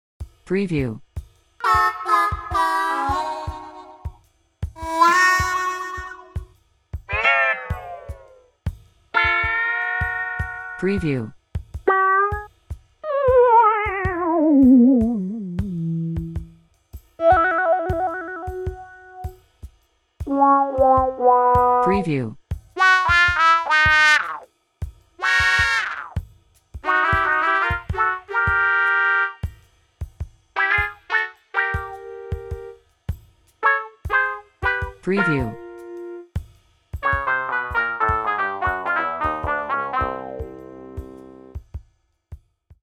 Casual Looser Sounds Watermarked with beats.mp3